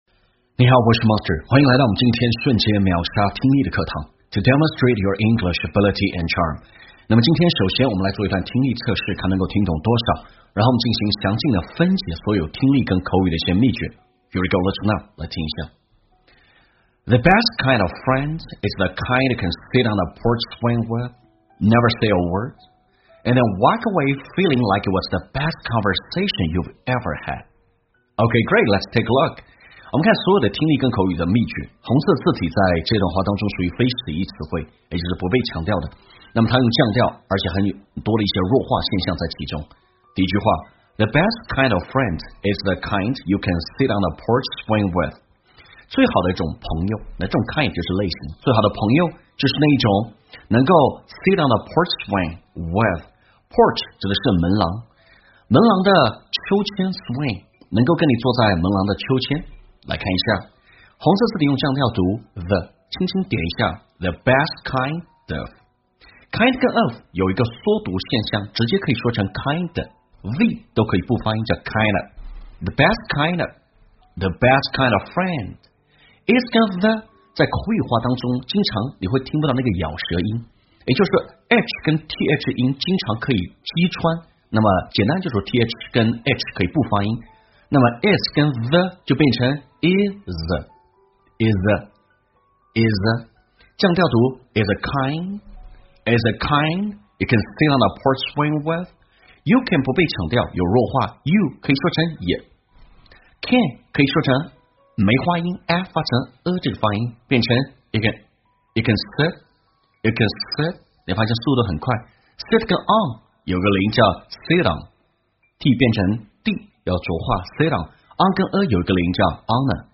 在线英语听力室瞬间秒杀听力 第390期:最好的朋友类型的听力文件下载,栏目通过对几个小短句的断句停顿、语音语调连读分析，帮你掌握地道英语的发音特点，让你的朗读更流畅自然。